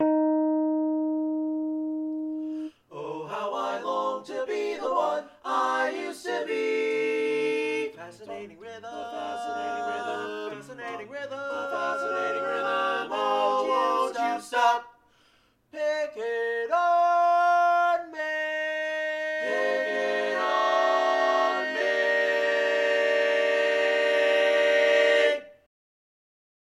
Key written in: E♭ Major
How many parts: 4
Type: Barbershop
All Parts mix:
Learning tracks sung by